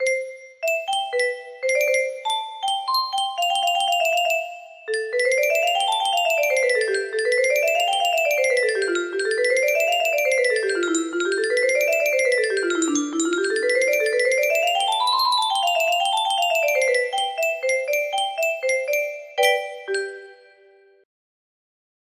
Mozart-Piano sonata music box melody
Wow! It seems like this melody can be played offline on a 15 note paper strip music box!